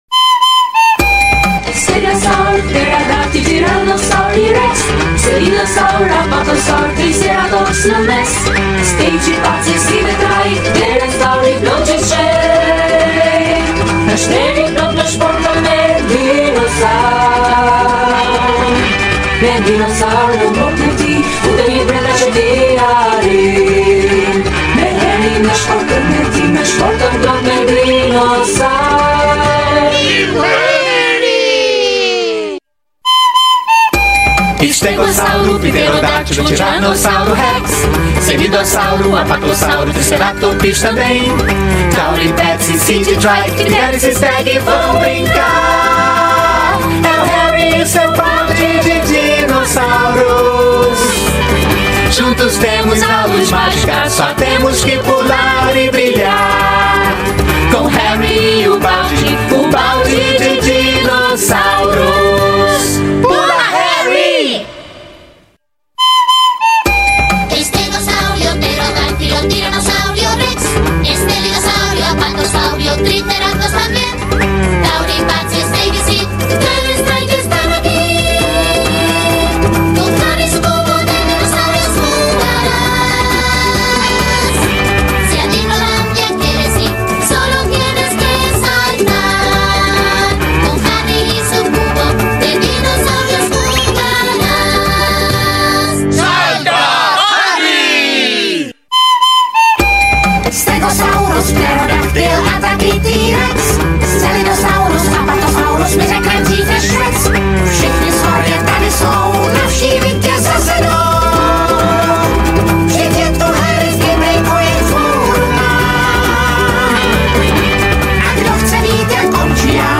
Theme Song Multilanguage (Dubs Only, 22 Languages)